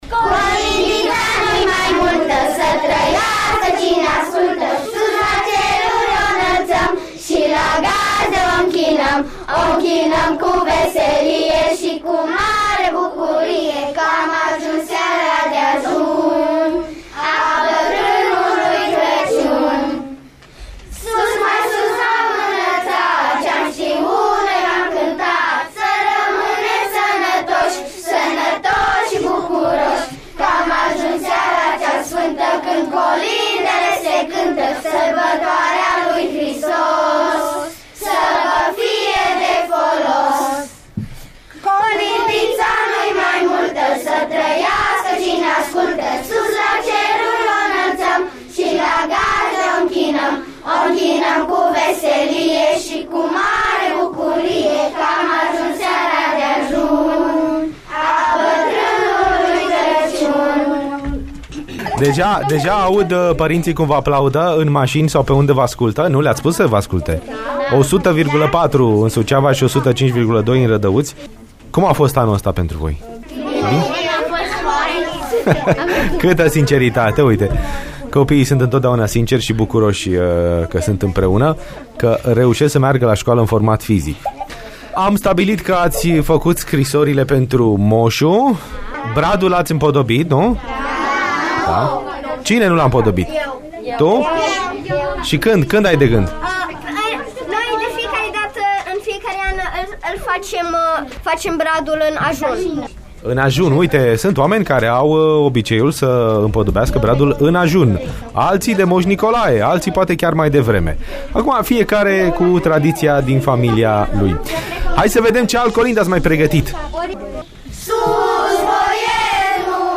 Am fost colindați de elevii de la Școala Gimnazială nr.3 Suceava
colinde.mp3